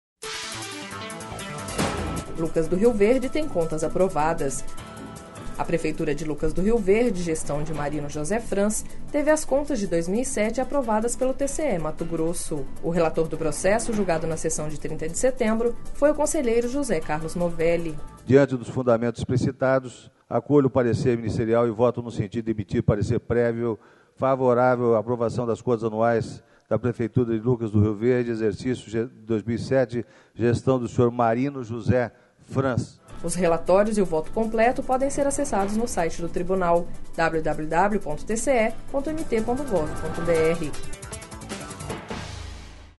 O relator do processo, julgado na sessão de 30 de setembro, foi o conselheiro José Carlos Novelli.// Sonora: José Carlos Novelli – conselheiro do TCE-MT